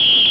Whistle Sound Effect
Download a high-quality whistle sound effect.
whistle-2.mp3